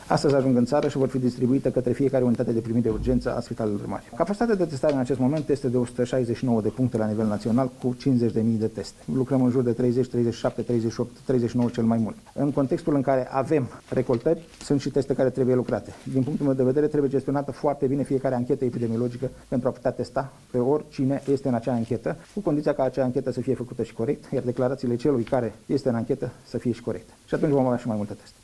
Prezent joi, la Satu Mare, Nelu Tătaru a afirmat că este nevoie de cel puțin trei săptămâni pentru a se opri creșterea transmiterii coronavirusului, și de încă trei săptămâni pentru a începe reducerea numărului de cazuri: